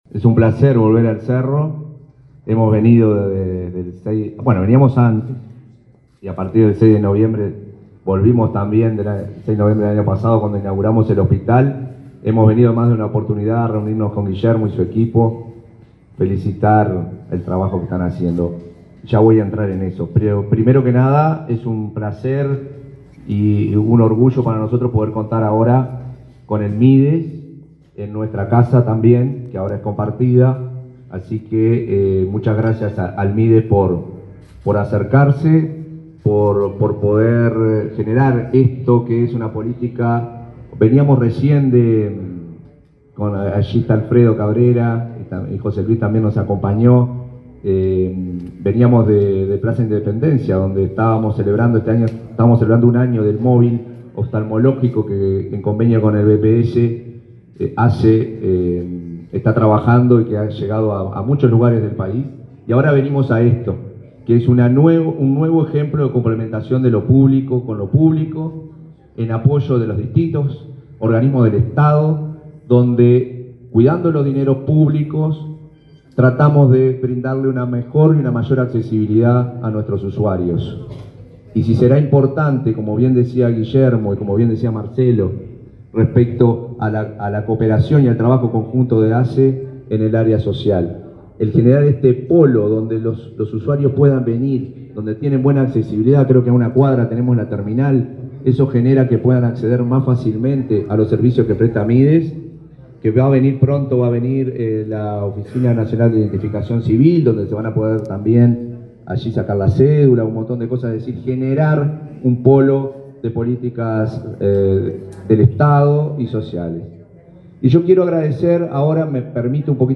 Palabras de autoridades en acto del Mides
Palabras de autoridades en acto del Mides 10/10/2024 Compartir Facebook X Copiar enlace WhatsApp LinkedIn El presidente de ASSE, Marcelo Sosa; el subsecretario de Salud Pública, José Luis Satdjian, y el ministro de Desarrollo Social, Alejandro Sciarra, participaron de la inauguración de una oficina territorial del Mides en el hospital del Cerro, en Montevideo.